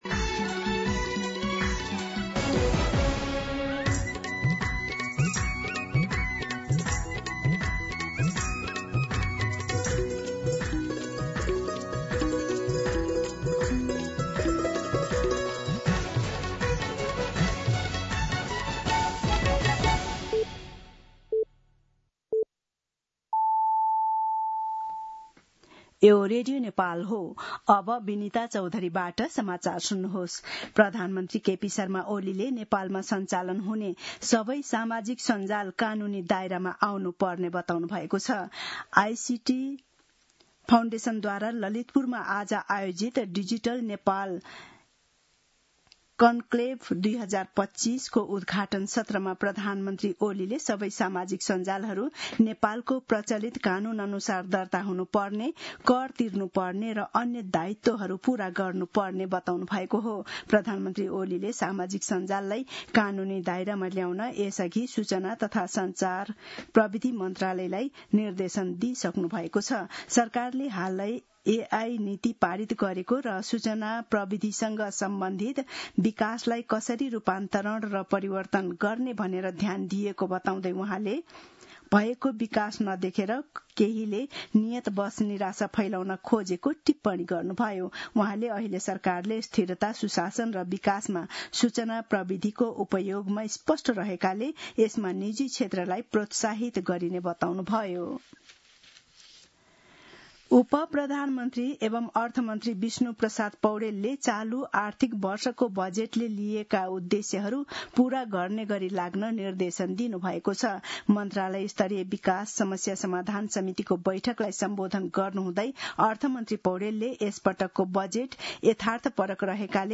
दिउँसो १ बजेको नेपाली समाचार : ३० साउन , २०८२
1-pm-Nepali-News-5.mp3